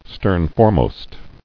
[stern·fore·most]